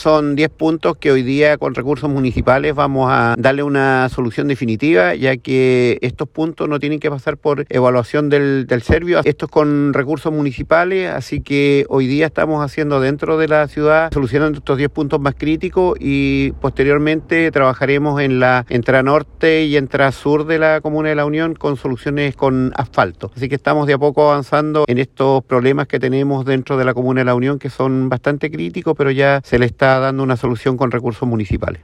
Audio-alcalde-Andres-Reinoso.mp3